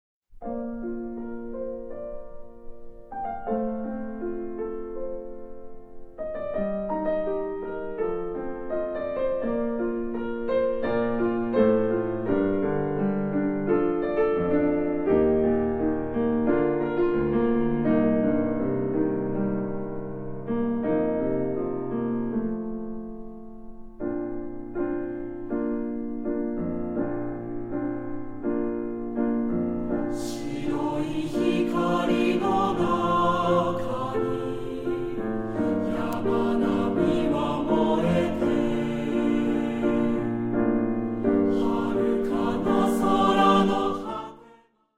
混声3部合唱／伴奏：ピアノ